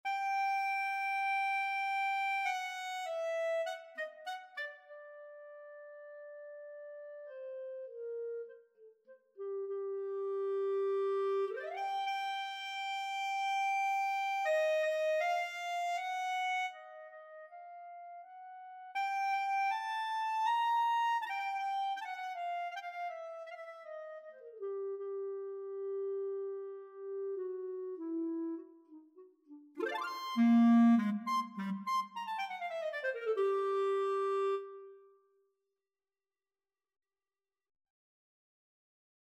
Nine-note scale a
clarinet-audio.mp3